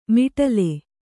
♪ miṭale